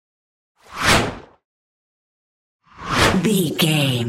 Whoosh fast x2
Sound Effects
Fast
futuristic
whoosh